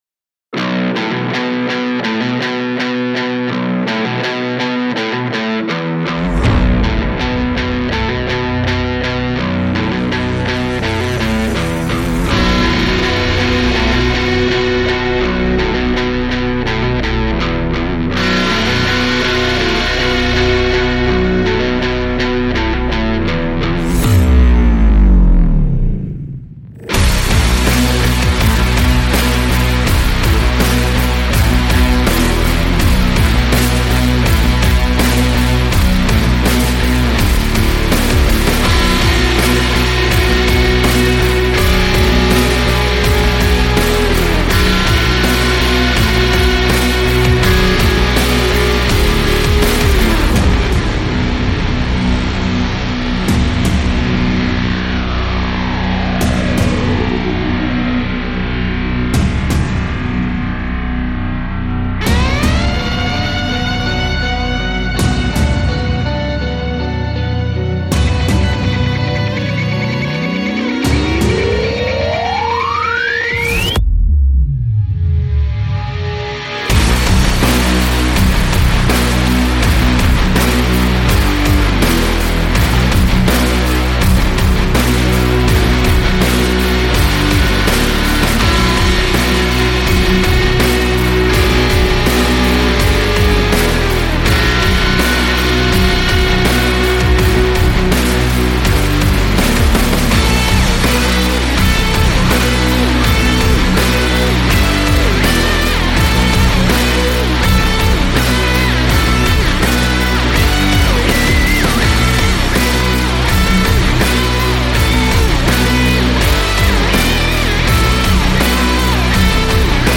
Жанр: alternative